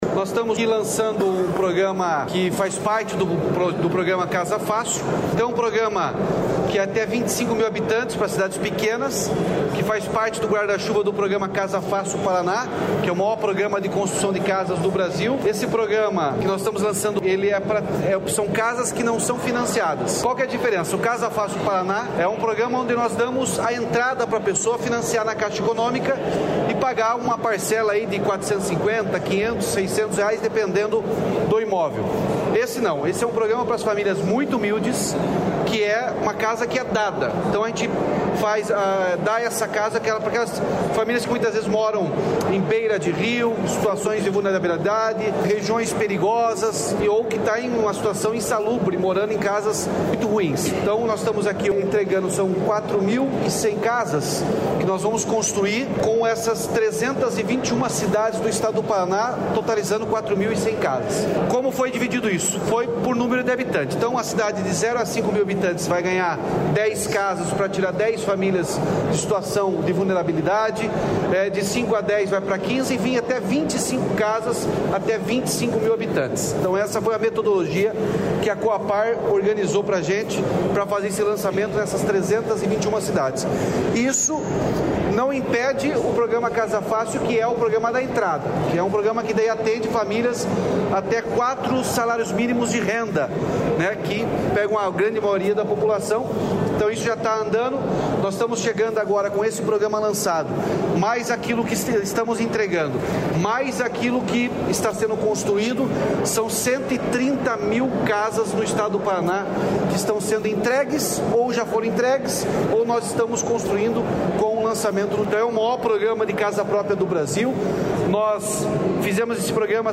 Sonora do governador Ratinho Junior sobre os R$ 533 milhões destinados para construção de casas em 324 municípios